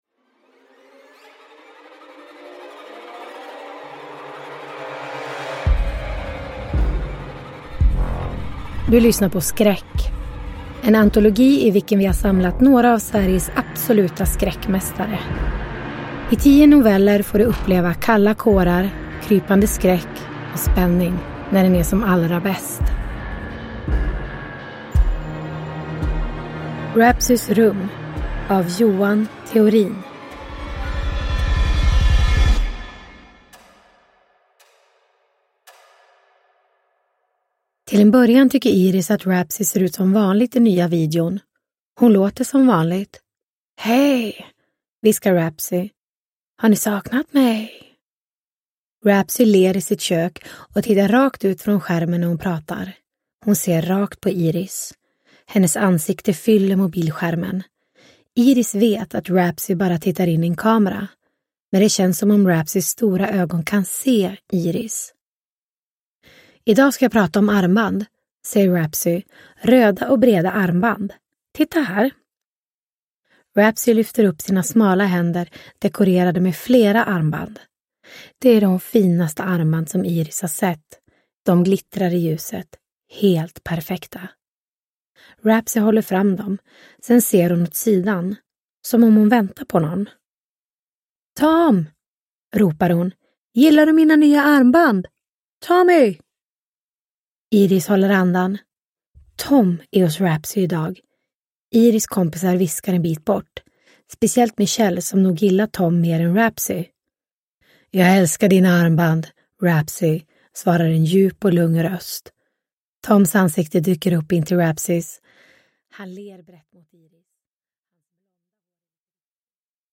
Skräck - Rapzys rum – Ljudbok – Laddas ner